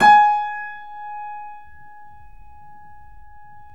Index of /90_sSampleCDs/Roland L-CD701/KEY_Steinway ff/KEY_Steinway M